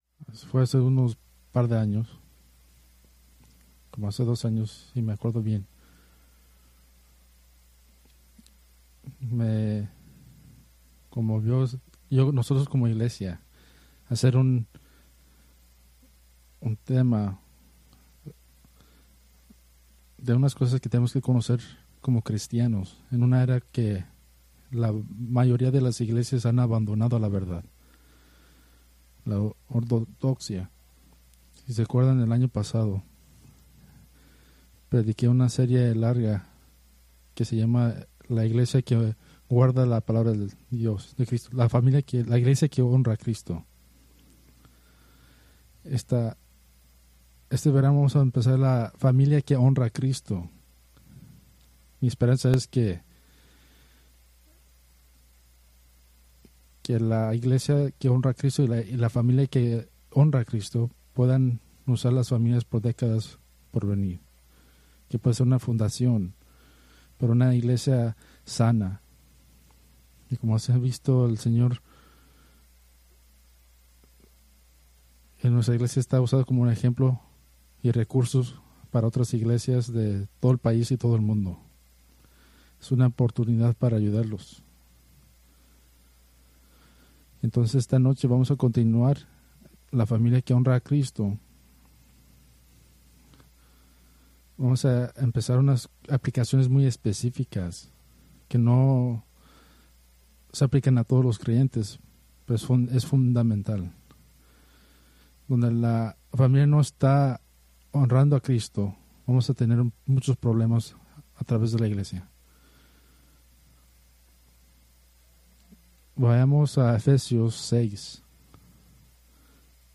Preached August 17, 2025 from Escrituras seleccionadas